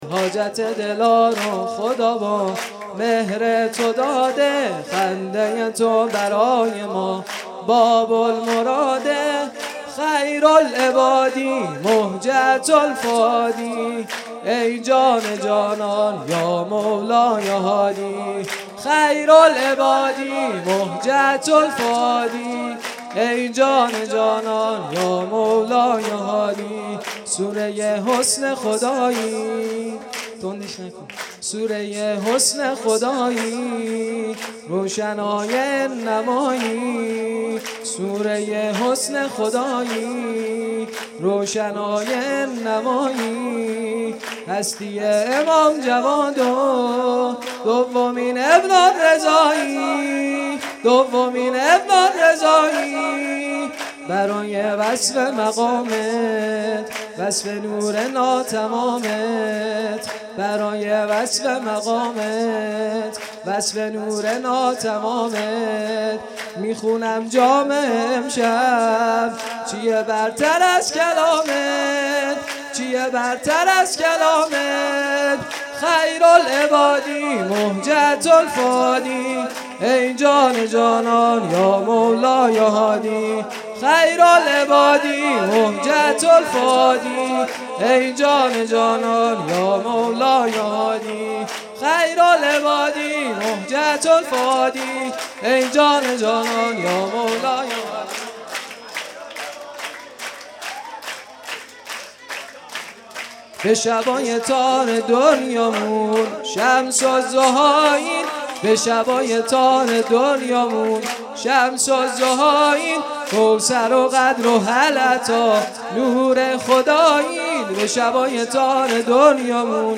جشن ولادت امام هادی علیه السلام